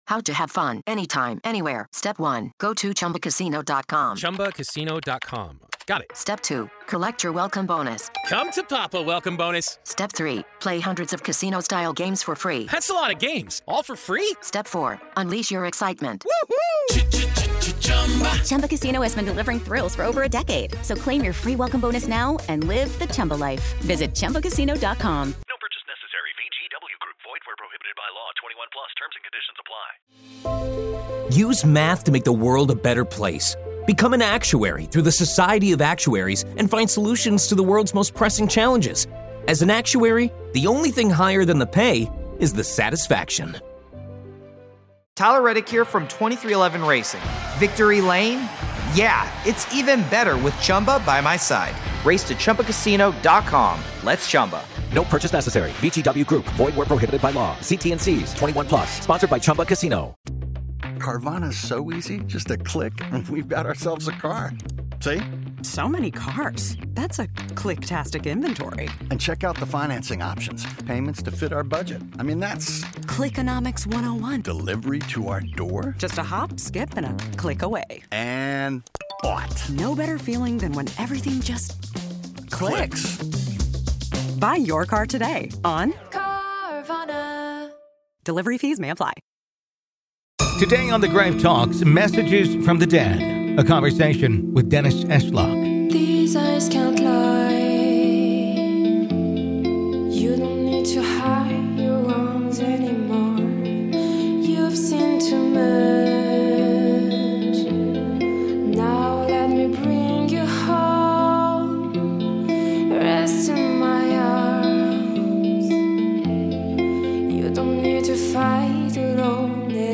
Today, Part One of our conversation